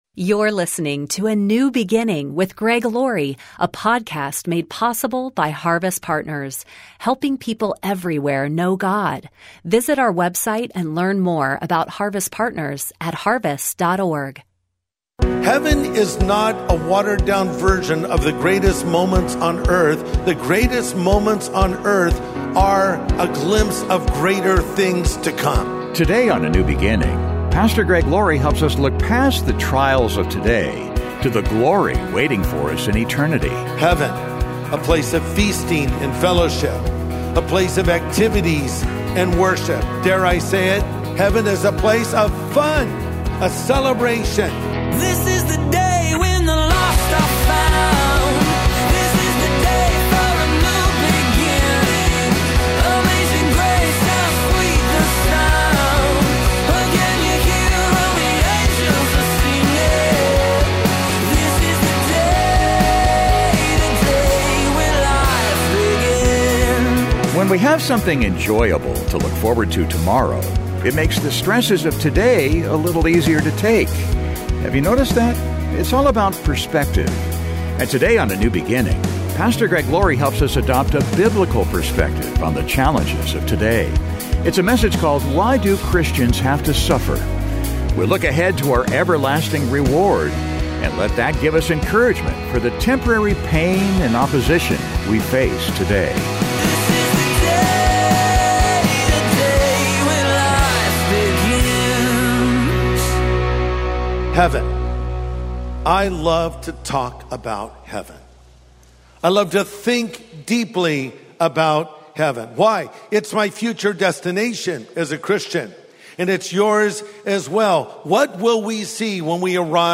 And today on A NEW BEGINNING, Pastor Greg Laurie helps us adopt a biblical perspective on the challenges of today.